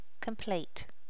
Initial check in of the sounds for the notify plugin.
complete.wav